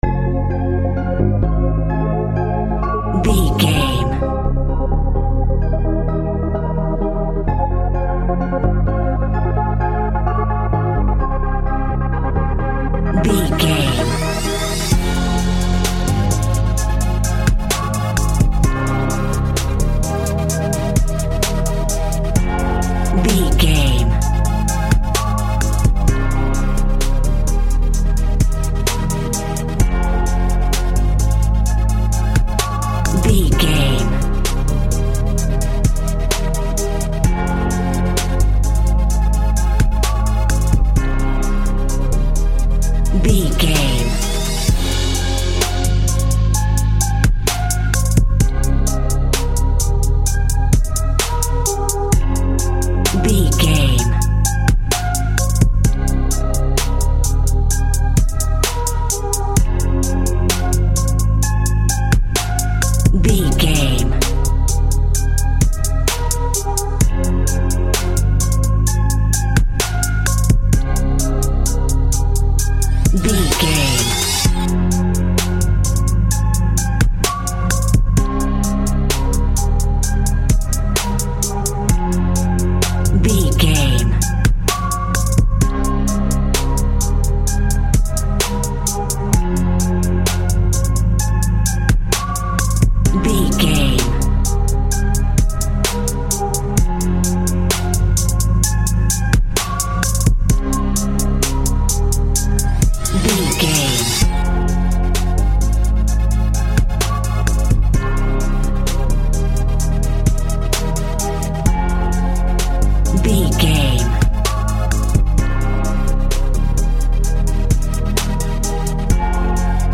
Instrumental Rap Beat.
Aeolian/Minor
chilled
laid back
groove
hip hop drums
hip hop synths
piano
hip hop pads